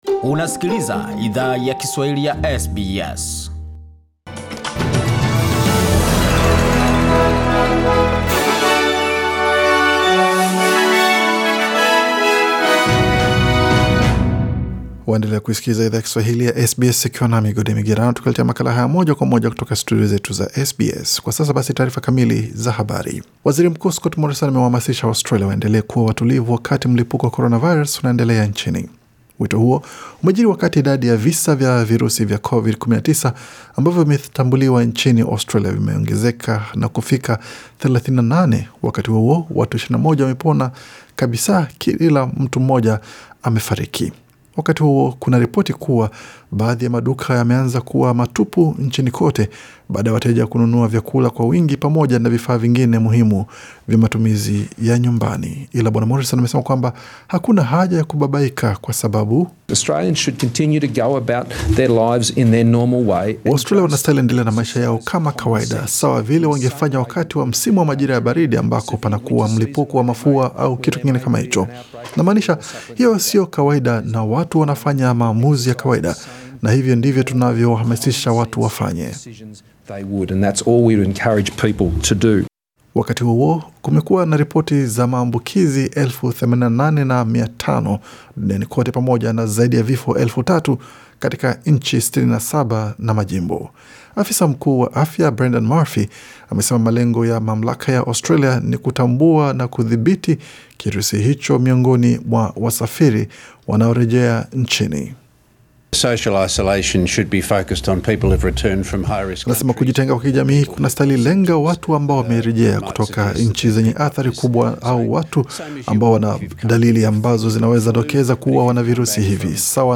Taarifa za habari:Waaustralia waombwa waendelee kuwa watulivu licha ya ongezeko yavisa vya coronavirus